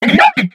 Fichier:Cri 0505 XY.ogg — Poképédia
Cri de Miradar dans Pokémon X et Y.